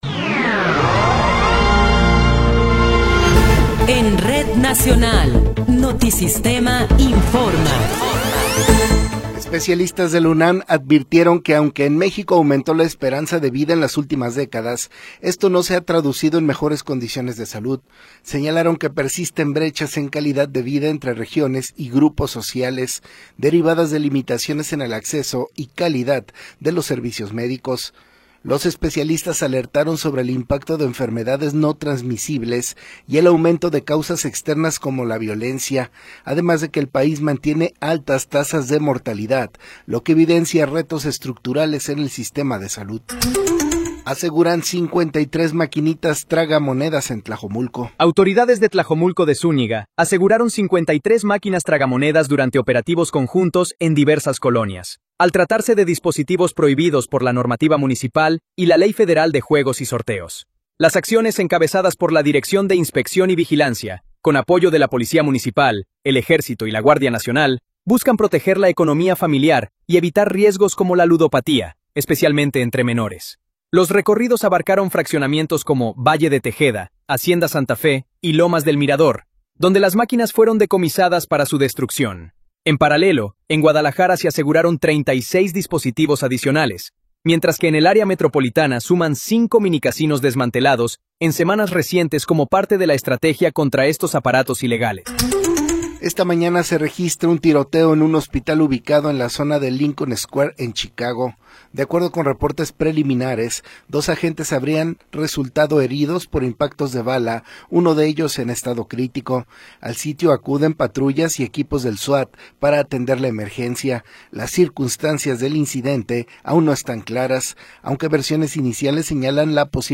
Noticiero 12 hrs. – 25 de Abril de 2026